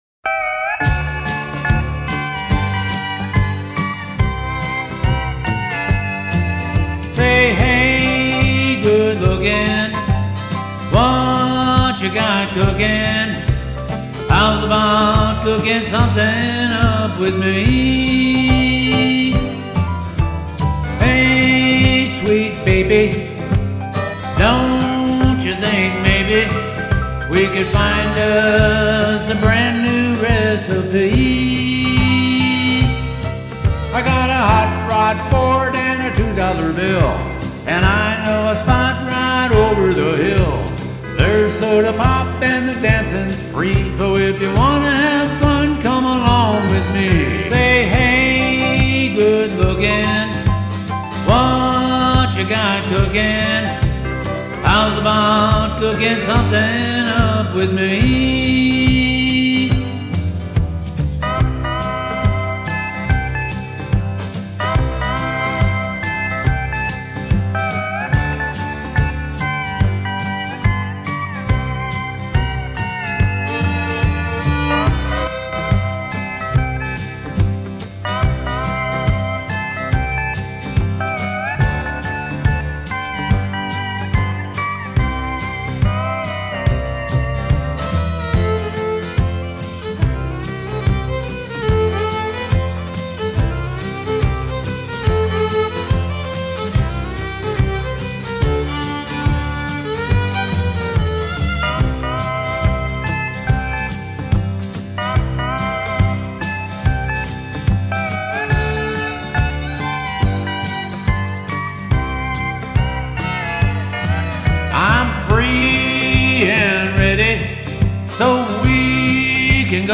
Country Blues & Boogies